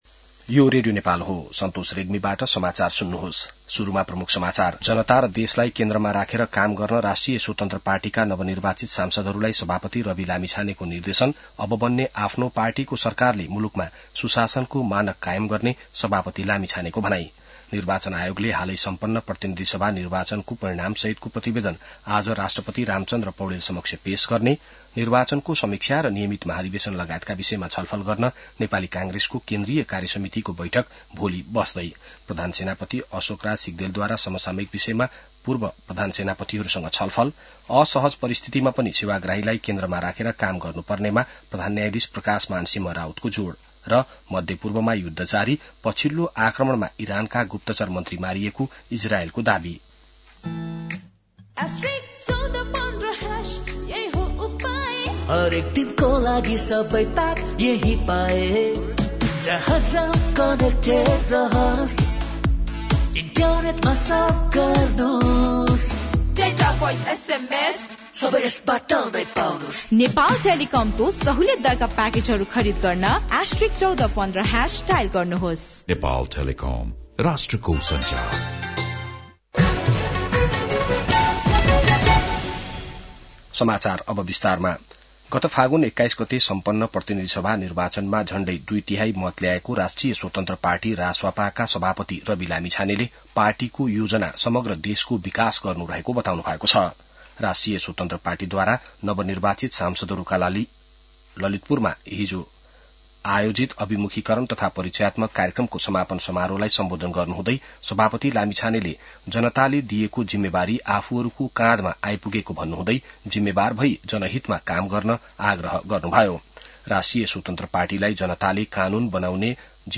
बिहान ७ बजेको नेपाली समाचार : ५ चैत , २०८२